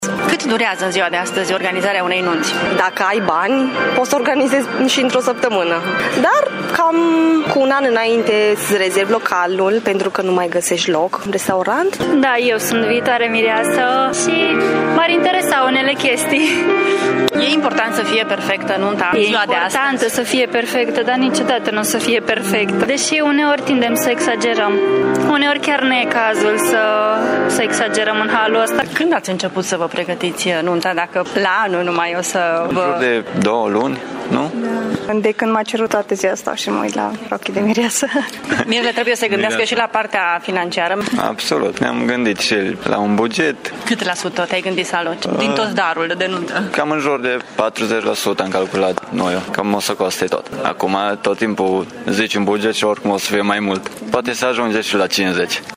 Viitorii miri din Tîrgu-Mureș spun că e important ca nunta lor să fie perfectă și organizată până în cele mai mici detalii, deși recunosc că uneori se exagerează: